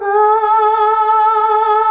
AAAAH   A.wav